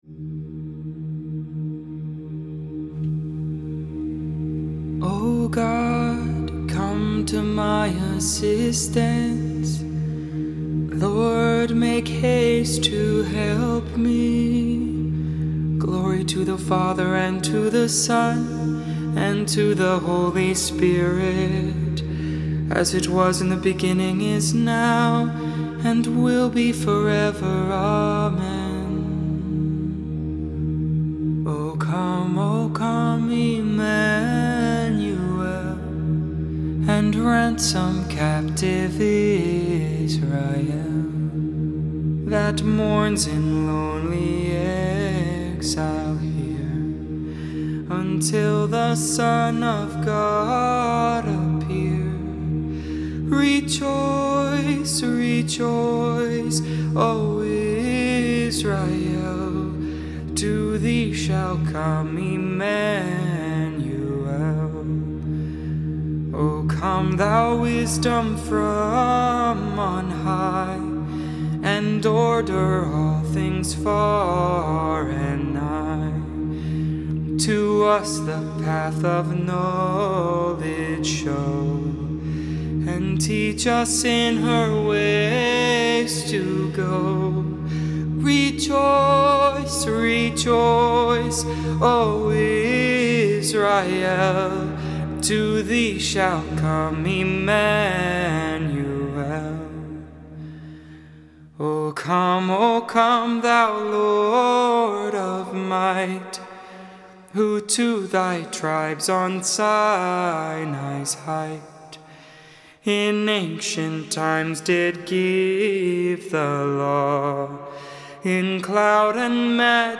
12.11.20 Vespers